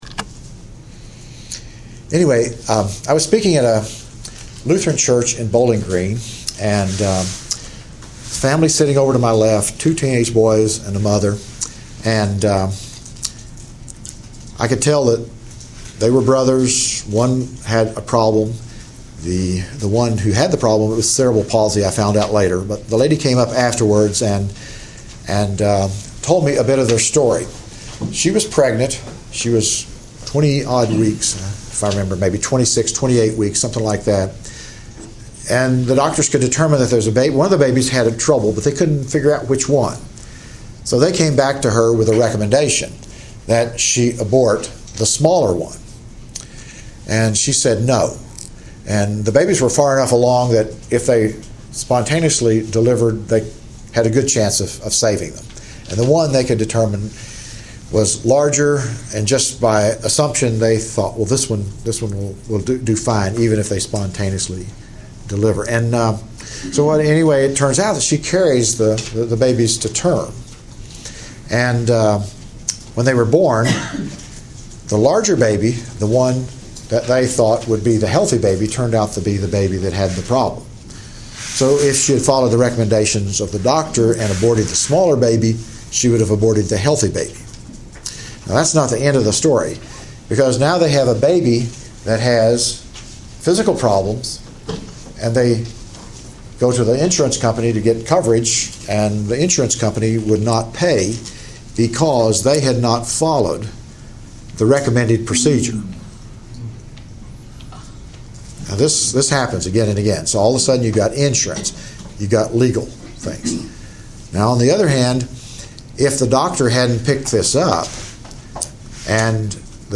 Address: Biotechnology and the Rise of Anti-Culture: Beyond Good, God and Man Recording Date